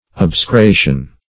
obsecration \ob`se*cra"tion\ ([o^]b`s[-e]*kr[=a]"sh[u^]n), n.